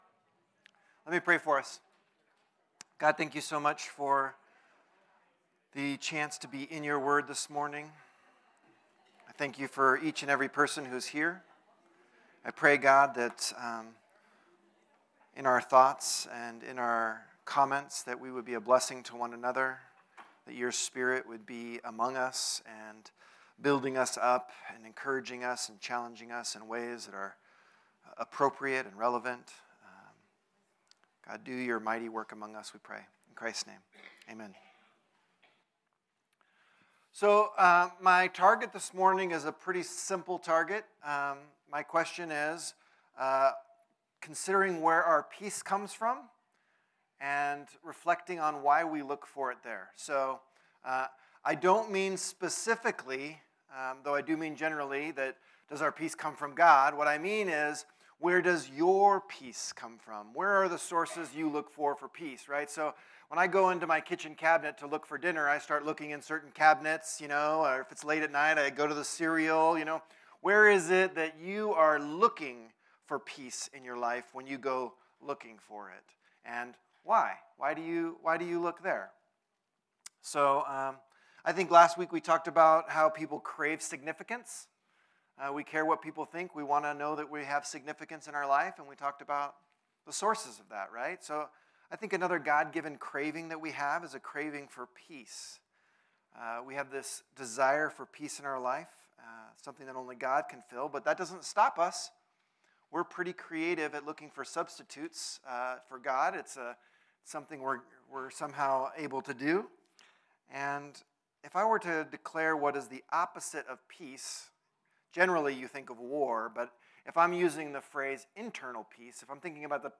Series: Broken Cisterns Type: Sunday School